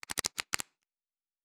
pgs/Assets/Audio/Fantasy Interface Sounds/Cards Shuffle 2_08.wav at master - pgs - Gitea (Himawari)
Cards Shuffle 2_08.wav